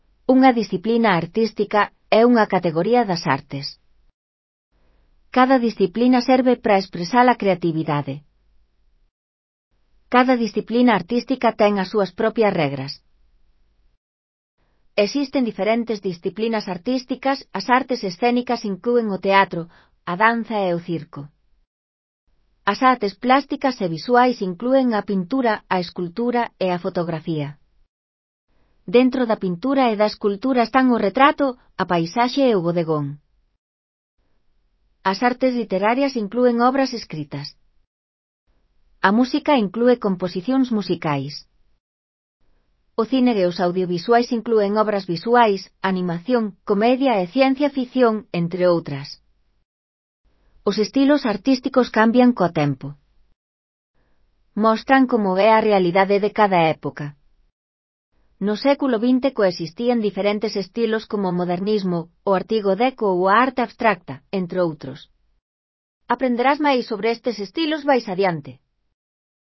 Elaboración propia (proxecto cREAgal) con apoio de IA, voz sintética xerada co modelo Celtia. Apoio auditivo 4 Xéneros arte.